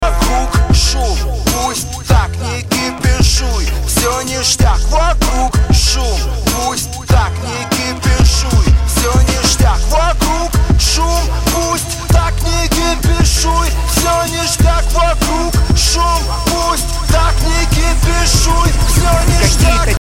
• Качество: 320, Stereo
русский рэп
блатные